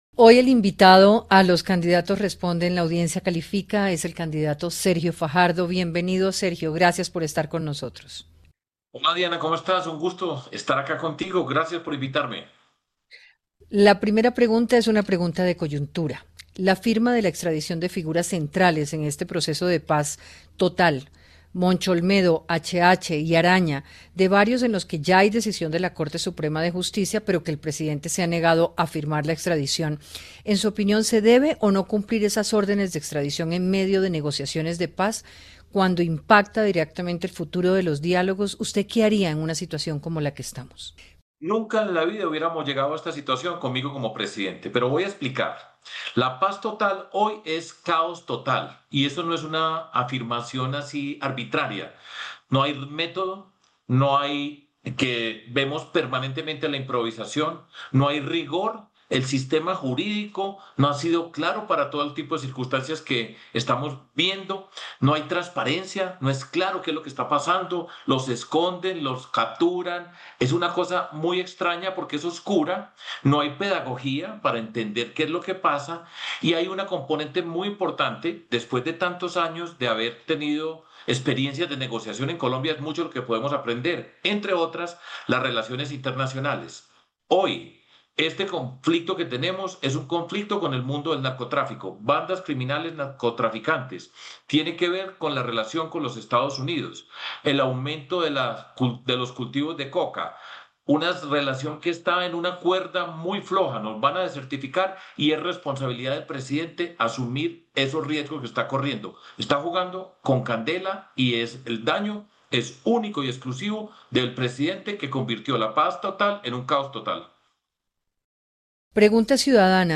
En diálogo con “Los candidatos responden, la audiencia califica”, el precandidato presidencial Sergio Fajardo planteó que en términos de paz total, en su gobierno nunca hubiera pasado lo que ha ocurrido durante esta administración, “la Paz total es caos total, no hay método, vemos improvisación, no hay rigor, hay un sistema jurídicos que no es claros y estamos viendo que no hay transparencia, no es claro qué pasa, los esconden, los capturan, no hay pedagogía y hay un componente importante en temas internacionales”.